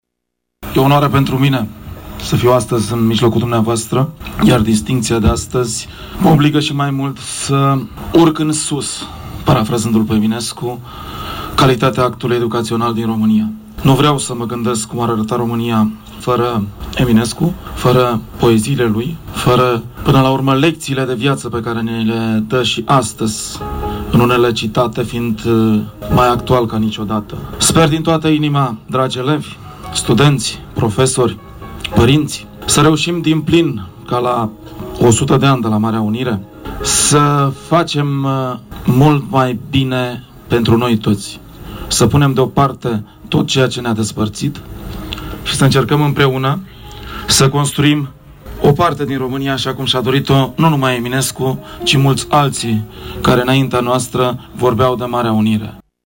În cadrul evenimentului comemorativ, desfășurat la Tîrgu-Mureș, în cinstea poetului național Mihai Eminescu, „La steaua…”, ministrul Educației Naționale, Liviu Pop a primit placheta „Eminescu”. Cu această ocazie el a declarat: